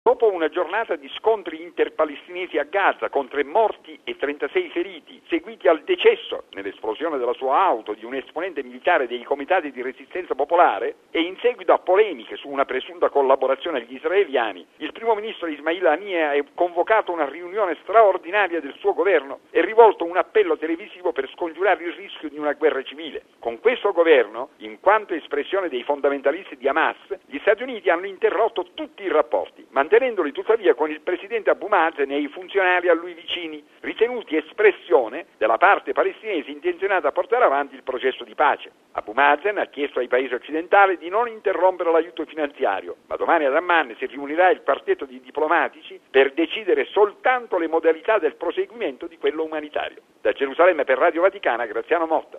(1° aprile 2006 - RV) Notte di intensi bombardamenti a Gaza, dove l’esercito israeliano ha risposto al lancio di razzi palestinesi sul territorio ebraico. Intanto, sul piano politico il nuovo governo palestinese degli estremisti di Hamas cerca di non perdere gli aiuti internazionali, di fronte al rifiuto di parte della comunità internazionale a trattare con l’esecutivo. Il servizio da Gerusalemme